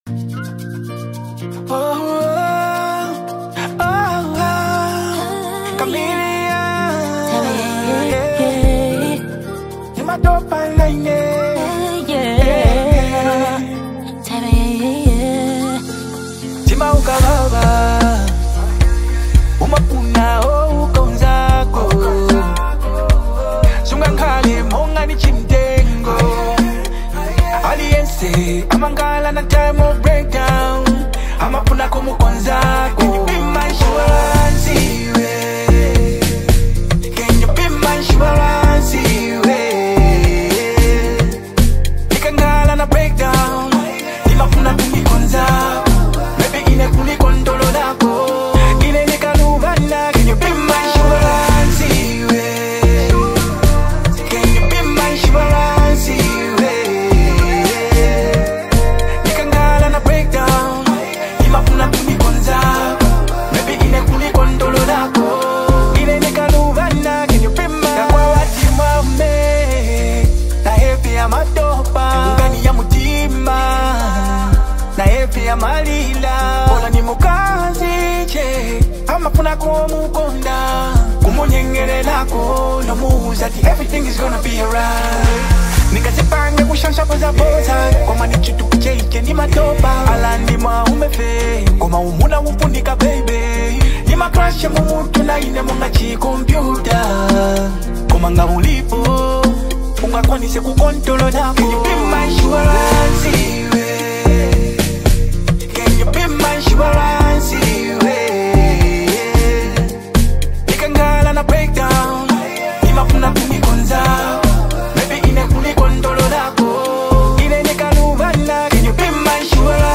A Romantic Duet